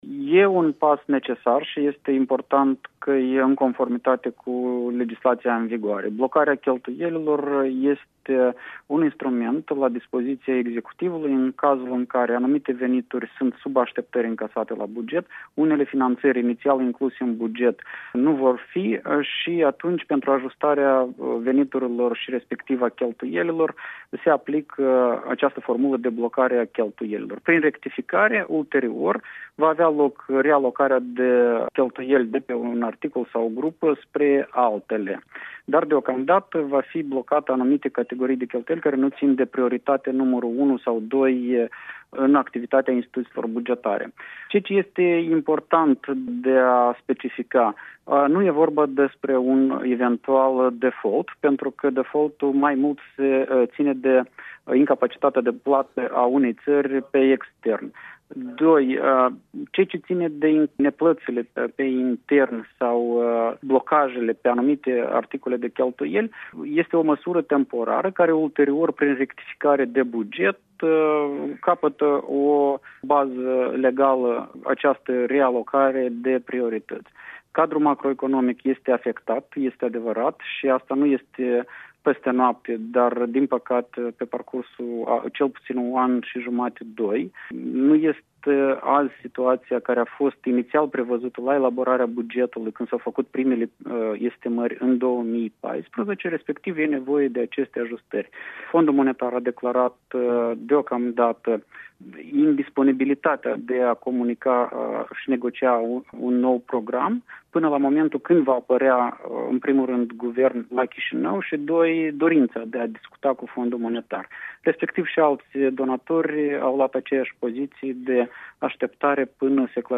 Interviu cu fostul ministru de finanţe Veaceslav Negruţa